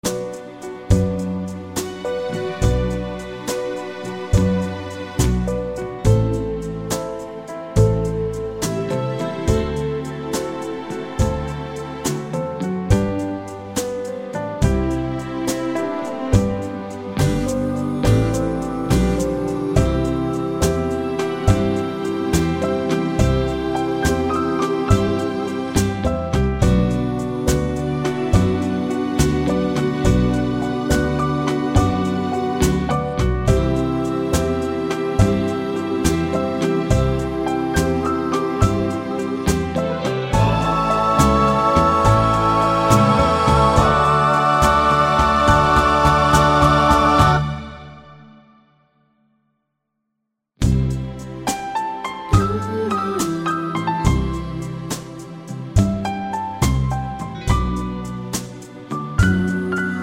no Backing Vocals Country (Female) 2:23 Buy £1.50